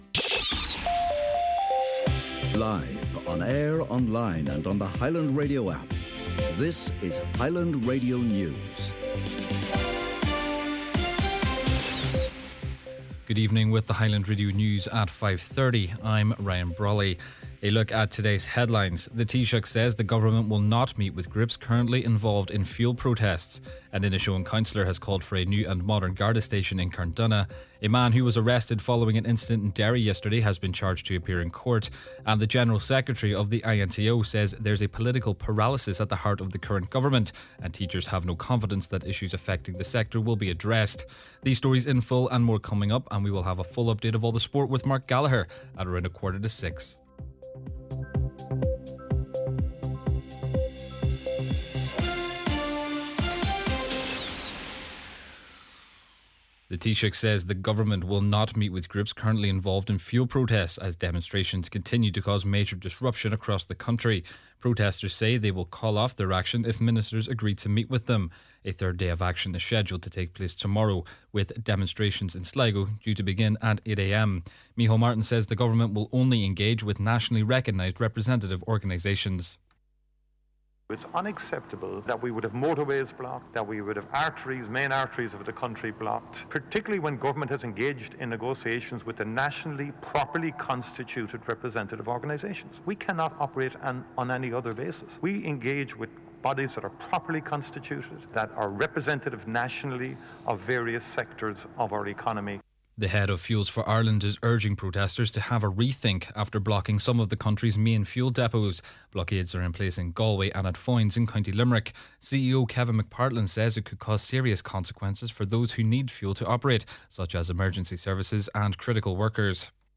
Main Evening News, Sport and Obituary Notices – Wednesday, April 8th - Highland Radio - Latest Donegal News and Sport